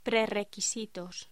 Locución: Prerrequisitos
locución
Sonidos: Voz humana